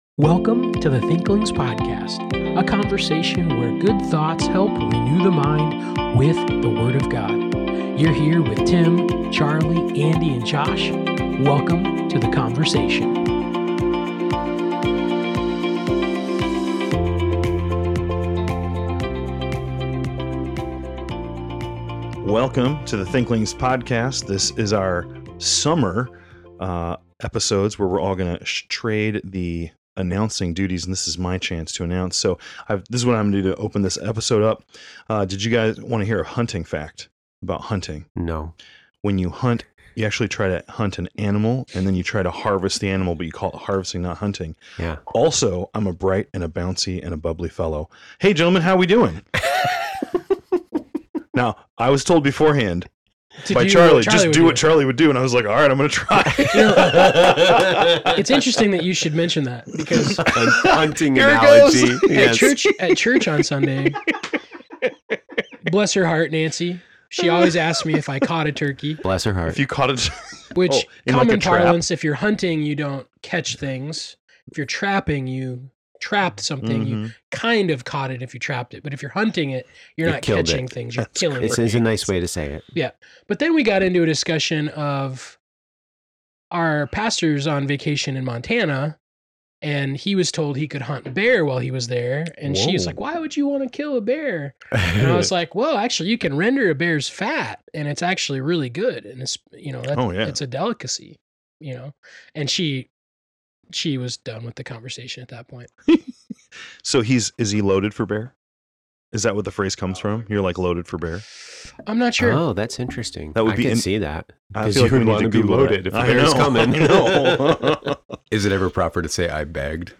We’re kicking off the summer episodes with a goofy intro, some literary reflection, and a final meditation! It’s a shorter book, a longer book, and a Scripture-rich close to the show.